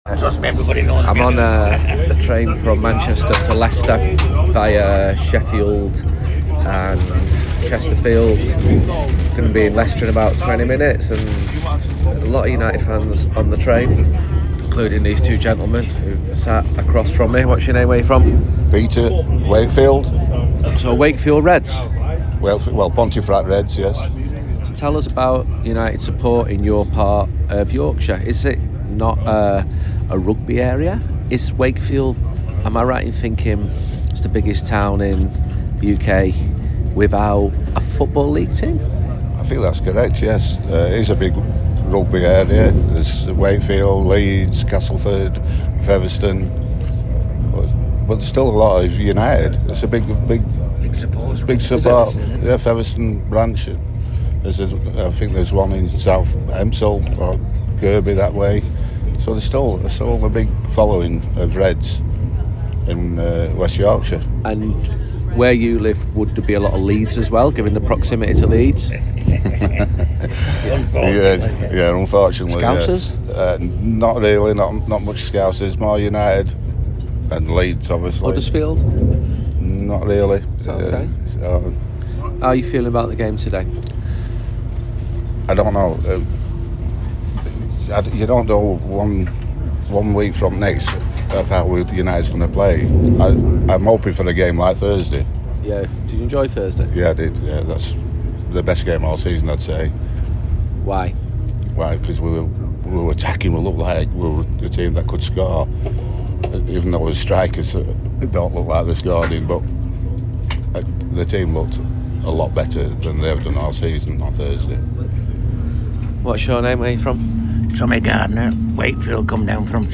spoke to fans and footballers